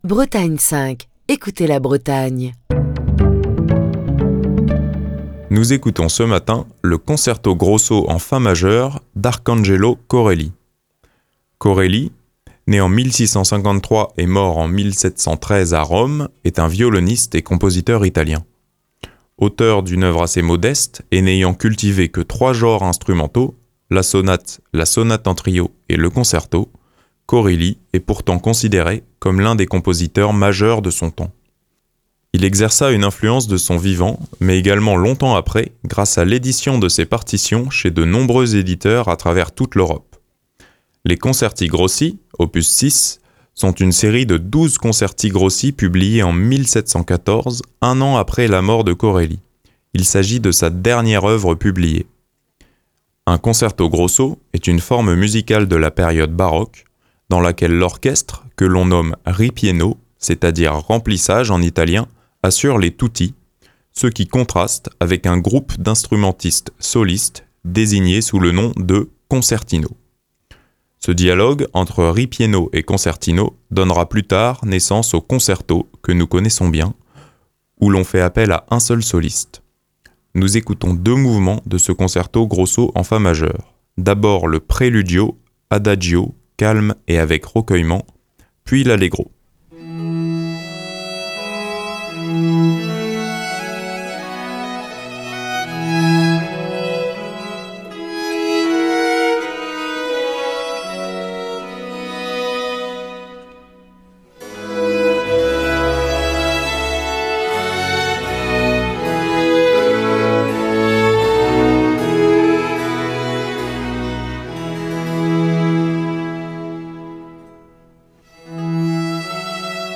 Adagio,calme et avec recueillement